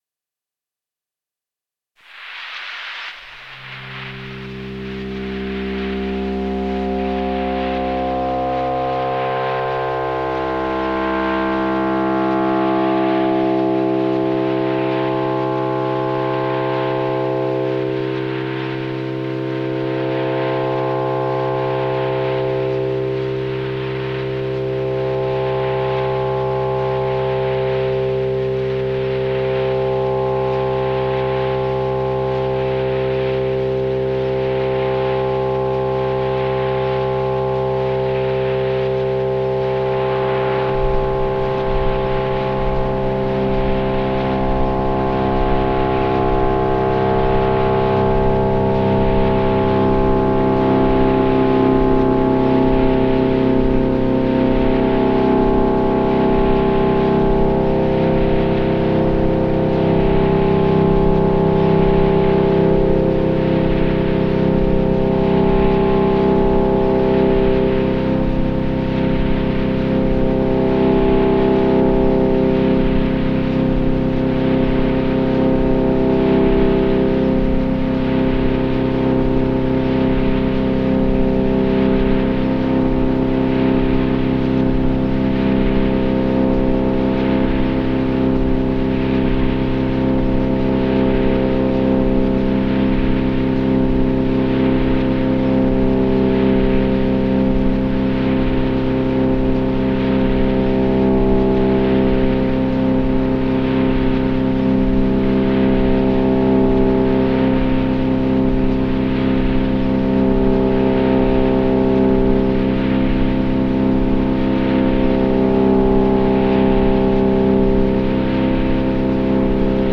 The low bitrate does a number on some of the details, but here’s a first live recording out from the 404 into Live, with no processing other than gain and a limiter.
This is a single drone note from my PreenFM2 and some processing of a few short clips of sounds from a few nature cams on youtube.